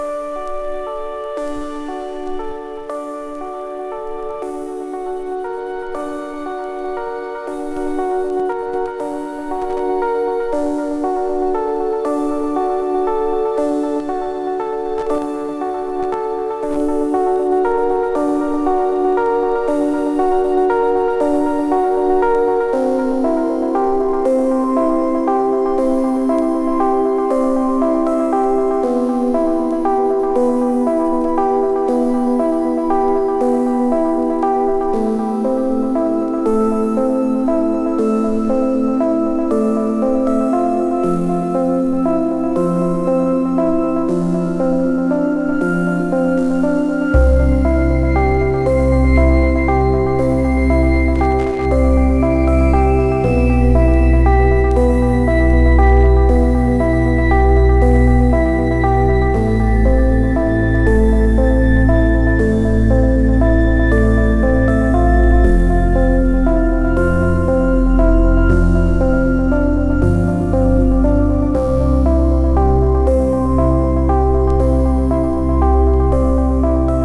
ゲーム音楽、Ambient、Vaporwave好きはぜひ！